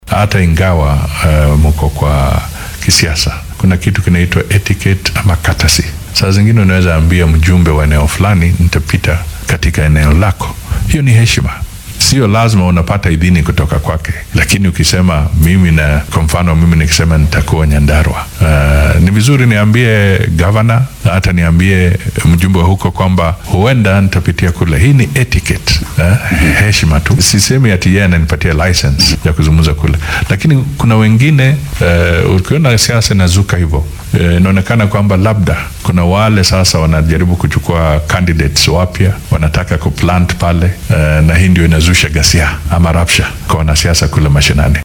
Dhanka kale ra’iisul wasaare ku xigeenkii hore ee dalka oo u warramay idaacadda Inooro ayaa ka hadlay dhacdo maalmo ka hor dhagaxaan lagu tuuray kolonyo baabuur ah oo uu la socday ku xigeenka madaxweynaha William Ruto xilli uu tagay ismaamulka Nyeri. Waxaa uu carrabka ku dhuftay inay aad muhiim u tahay in siyaasiyiinta ay ixtiraam muujiyaan oo musharrax kasta ismaamulka uu olole siyaasadeed ka sameynaya ku wargeliyo madaxda halkaasi laga soo doortay